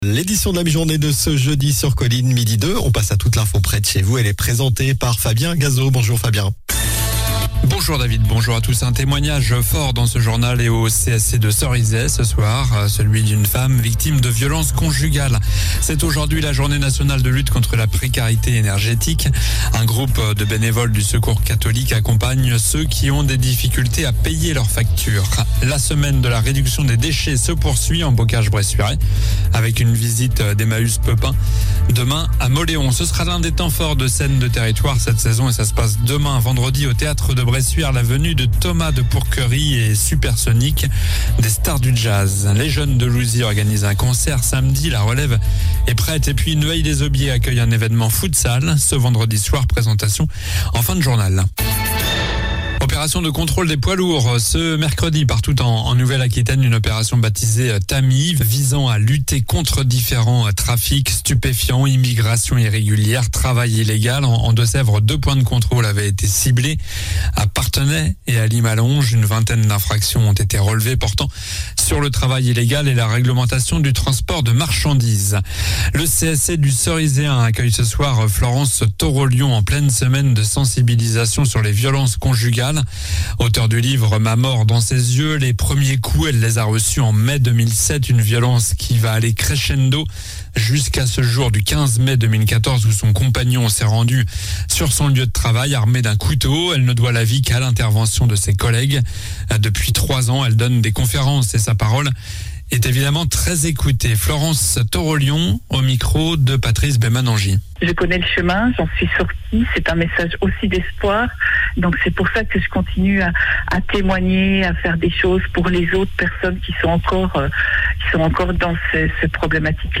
Journal du jeudi 24 novembre (midi)
- Un témoignage fort dans ce journal, celui d'une femme victime de violences conjugales. - C'est aujourd'hui la journée nationale de lutte contre la précarité énergétique.